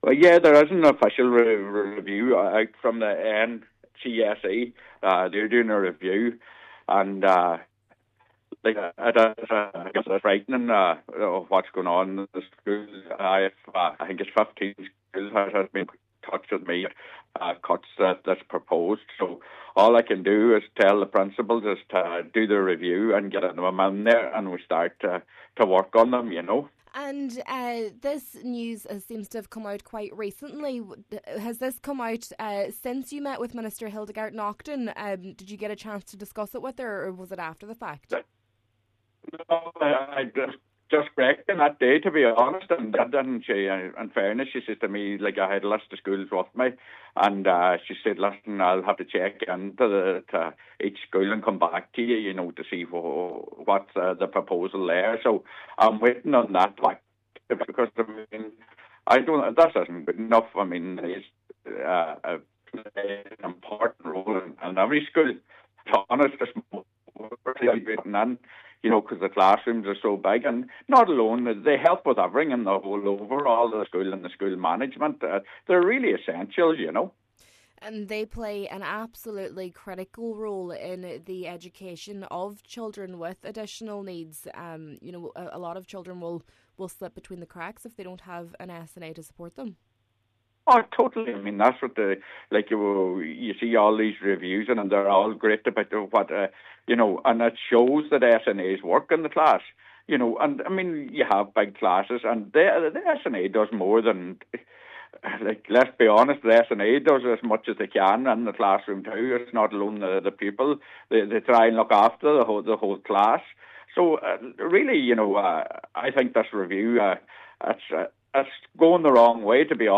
Senator Boyle says the work of SNA’s cannot be underestimated: